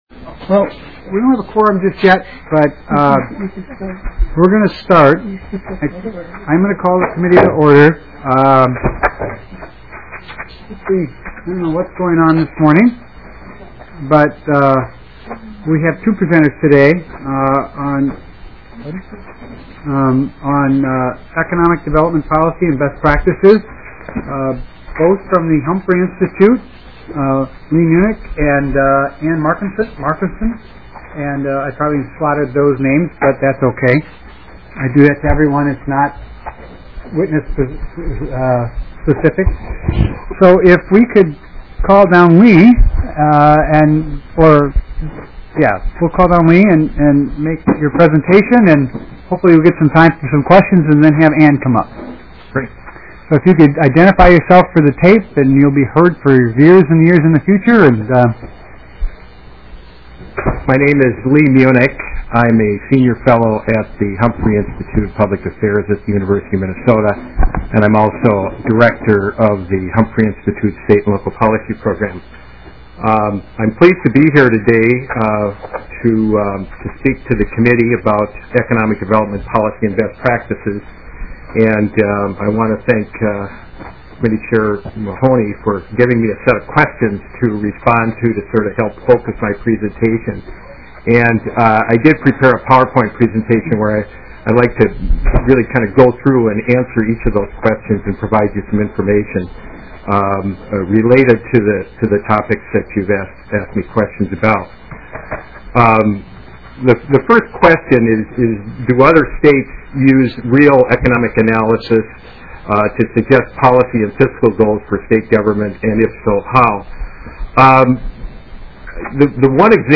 01:50 - Gavel, and presentation on economic development policy and best practices.